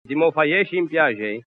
Si “ottiene” il dittongo “o-u” /'Ow/, che si trova riportato nella tabella dedicata ai “dittonghi discendenti” contenuta nell'articolo “I dittonghi e gli iati della lingua genovese” presente sul sito come “appendice” alle “Osservazioni sulla grafia di G. Casaccia”.